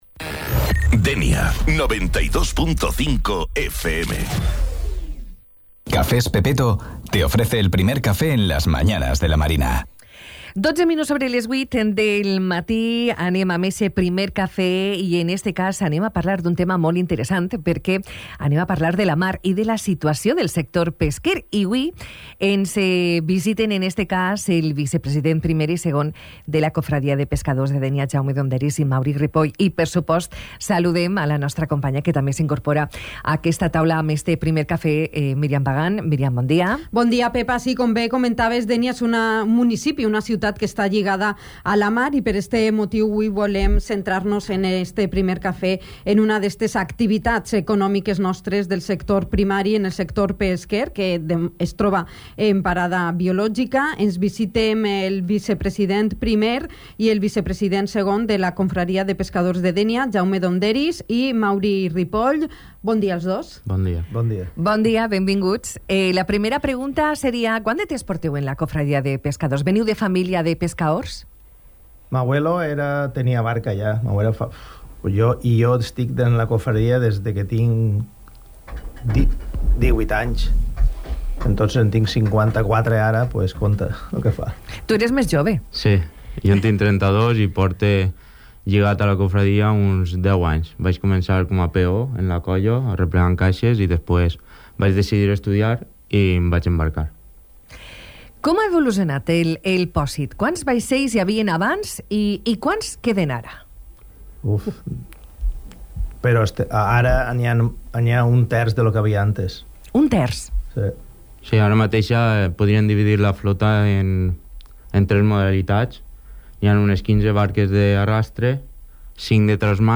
Entrevista-Cofradia-Pescadores.mp3